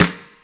• Eighties Trebly Jazz Snare Drum F Key 205.wav
Royality free snare sound tuned to the F note.
eighties-trebly-jazz-snare-drum-f-key-205-kD9.wav